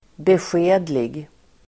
Ladda ner uttalet
Uttal: [besj'e:dlig]